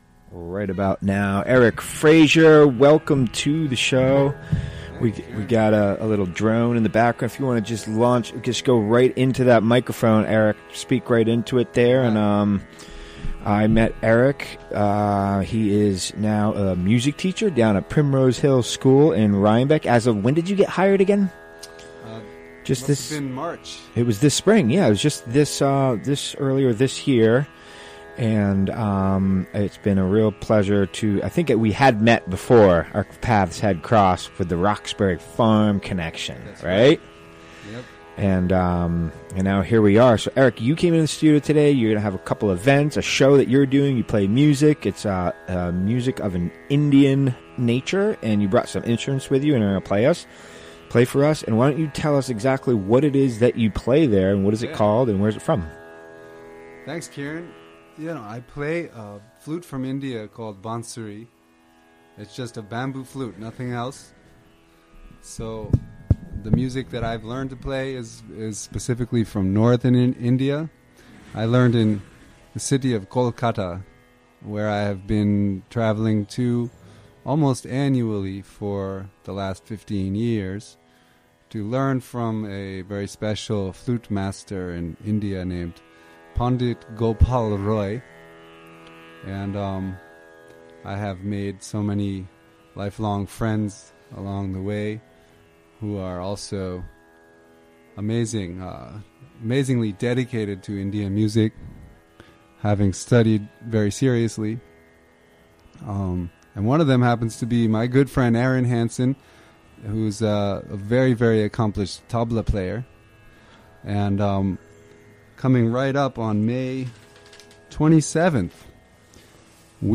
Recorded during the WGXC Afternoon Show Monday, May 15, 2017.